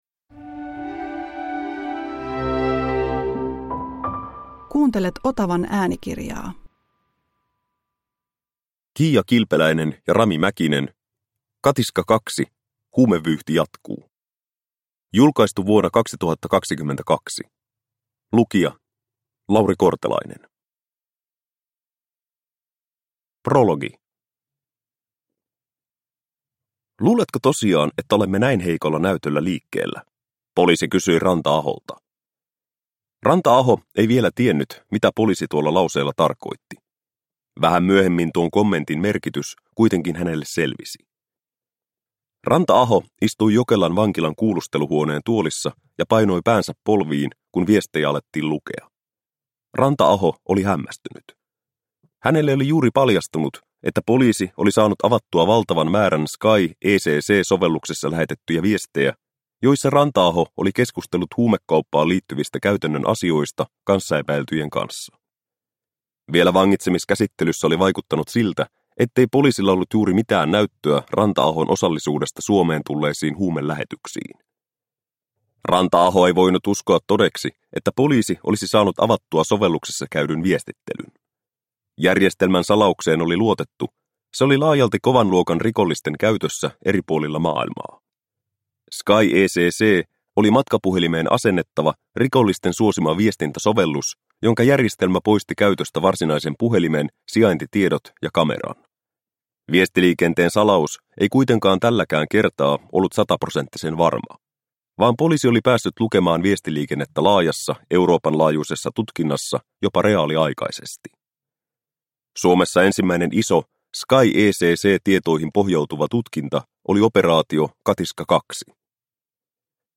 Katiska II – Ljudbok – Laddas ner